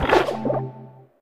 Cri de Fragroin mâle dans Pokémon Écarlate et Violet.